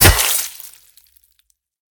generalswing.ogg